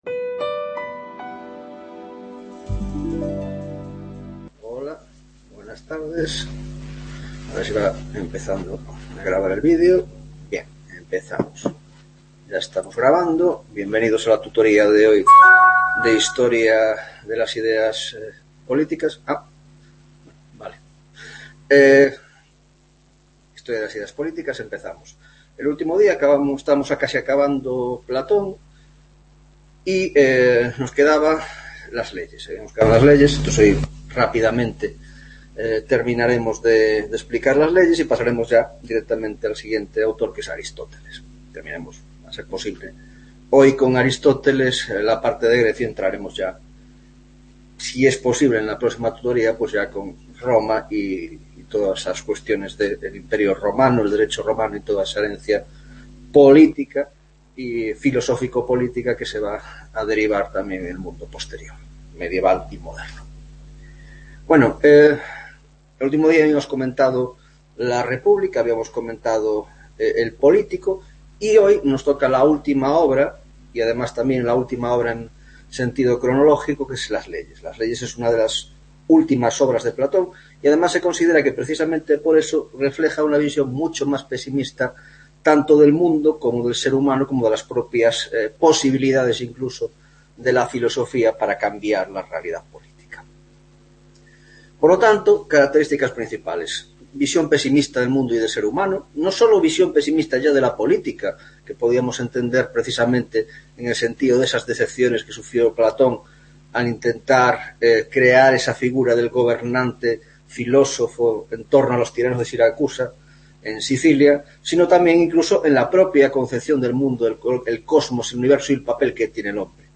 4ª Tutoria de Historia de las Ideas Políticas (Grado de Ciencias Políticas y Grado de Sociologia) - Aristóteles